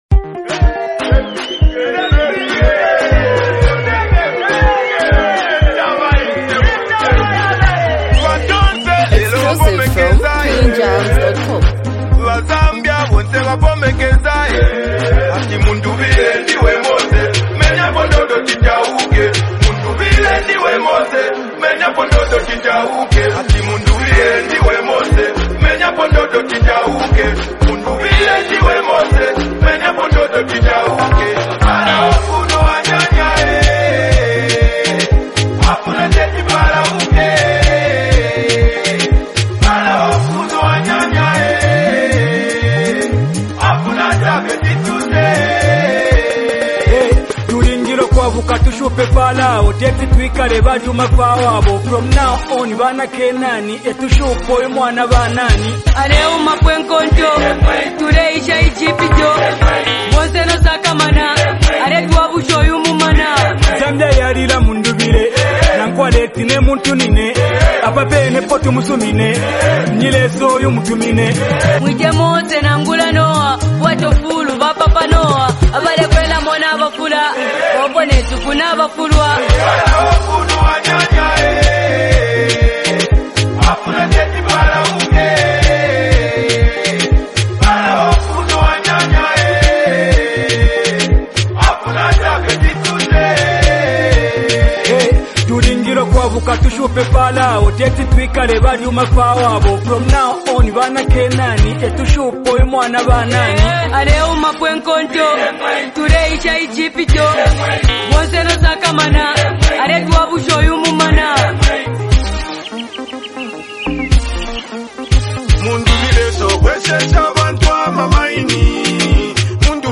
street anthem